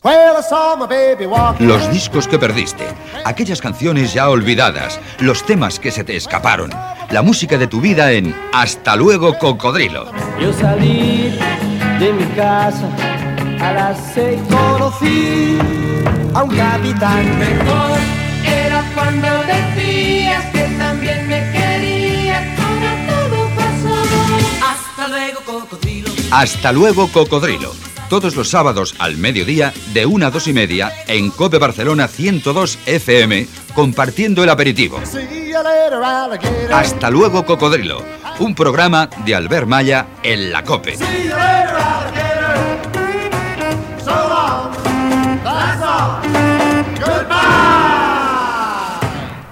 Promoció del programa de música "revival"
Musical
FM